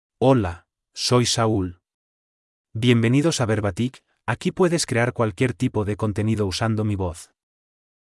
MaleSpanish (Spain)
Saul is a male AI voice for Spanish (Spain).
Voice sample
Listen to Saul's male Spanish voice.
Saul delivers clear pronunciation with authentic Spain Spanish intonation, making your content sound professionally produced.